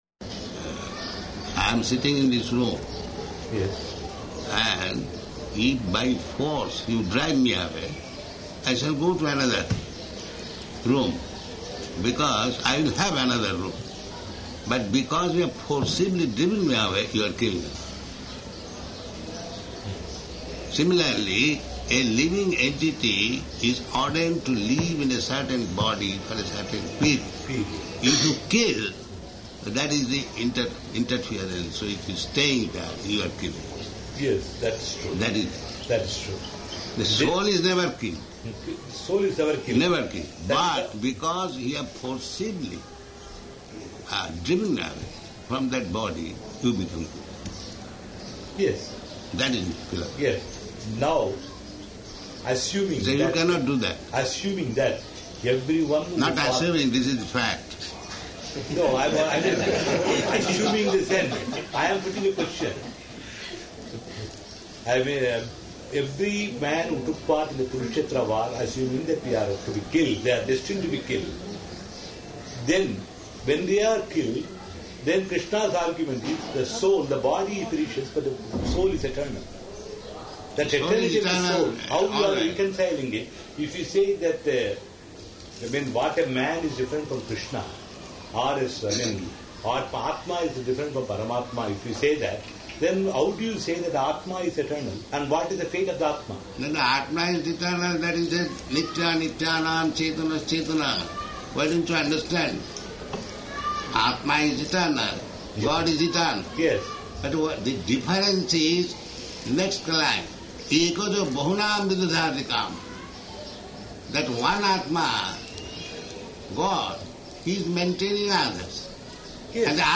Room Conversation
Room Conversation --:-- --:-- Type: Conversation Dated: August 25th 1976 Location: Hyderabad Audio file: 760825R1.HYD.mp3 Prabhupāda: I am sitting in this room.